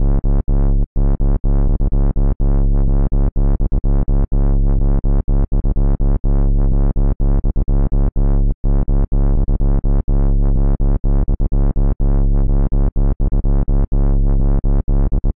Tech House Bass Rhythm Punchy - C sharp.wav
Loudest frequency 83 Hz
Channels Stereo
tech_house_bass_rhythm_15_punchy_-_c_sharp_6l5.ogg